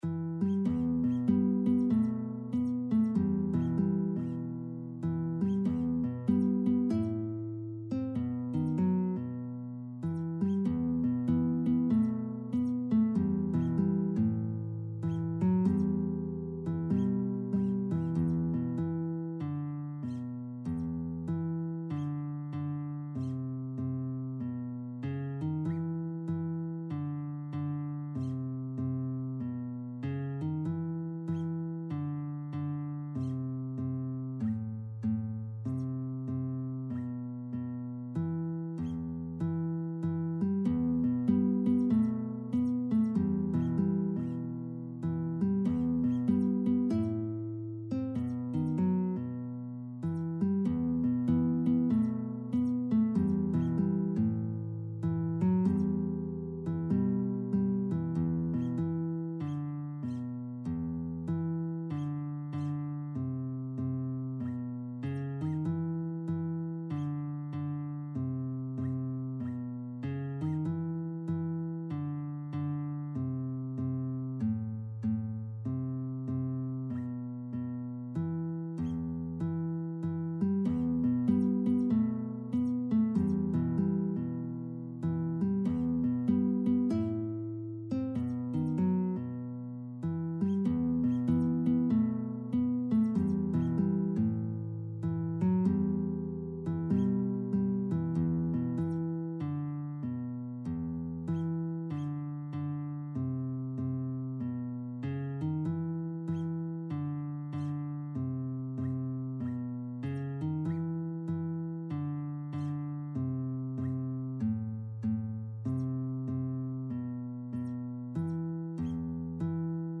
Guitar version
Medium Swing = 96
4/4 (View more 4/4 Music)
Guitar  (View more Intermediate Guitar Music)
Jazz (View more Jazz Guitar Music)